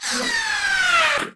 auto_flyby1.wav